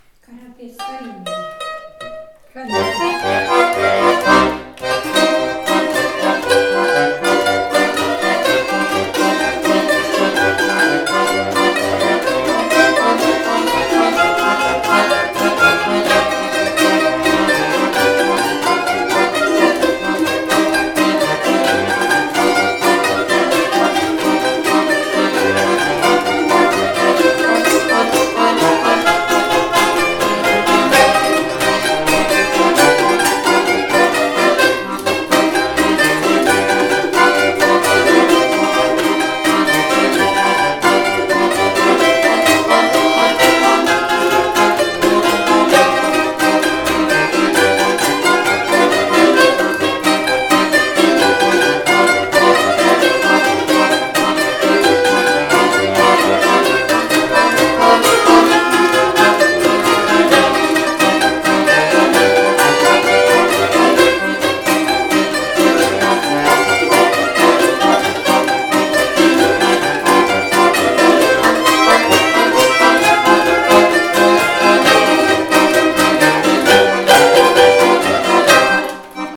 В ансамбле мандолина является солирующим инструментом и отчасти берет на себя функцию скрипки.
Их игра отличается слаженностью, сбалансированностью звучания и большой виртуозностью.
01 Карапет. Плясовой инструментальный наигрыш в исполнении ансамбля народных музыкантов из х. Колесникова Вейделевского р-на Белгородской обл.